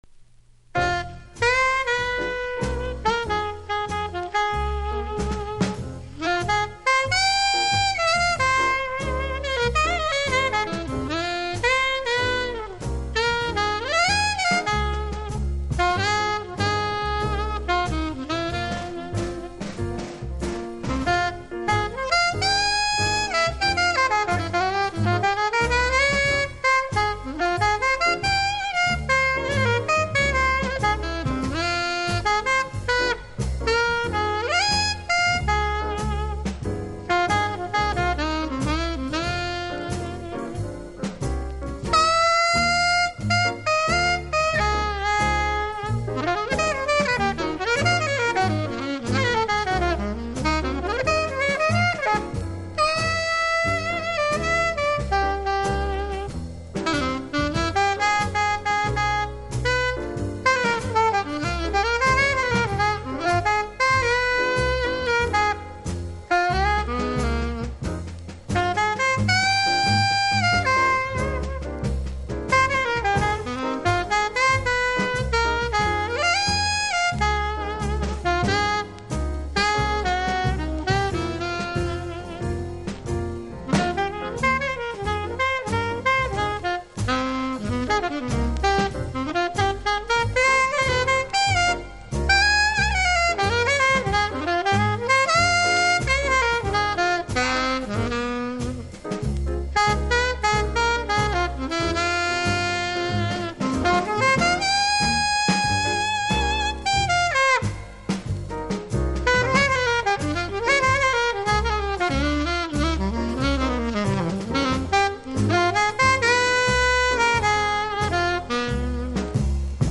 ワンホーンもの名盤（1960年）